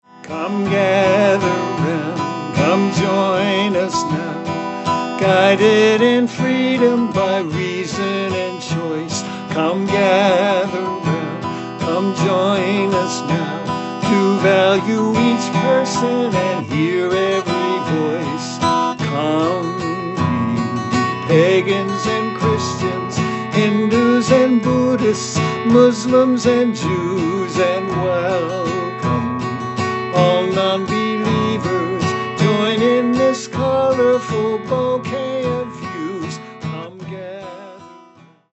a welcoming opening hymn